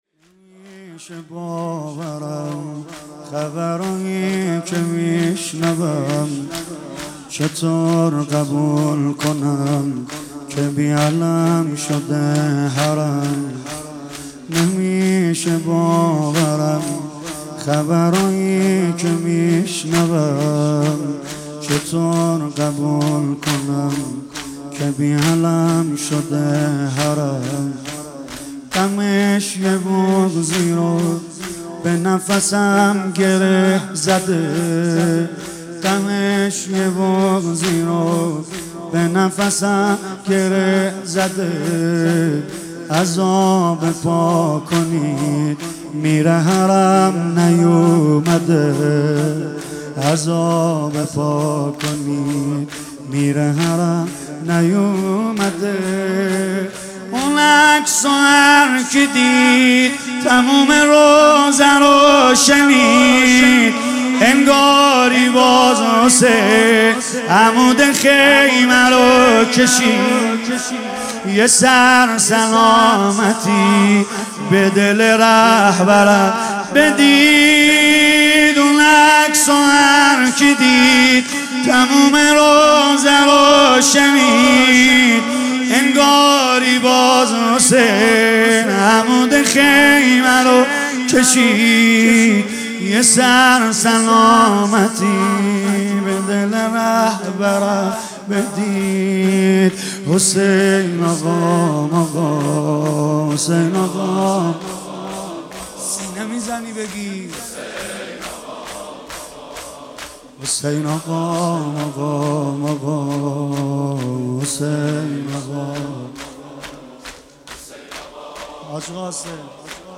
مداحی
در ویژه‌برنامه‌ای به مناسبت شهادت سردار حاج قاسم سلیمانی در هیئت فدائیان حسین اصفهان به مداحی درباره شهید قاسم سلیمانی پرداخت.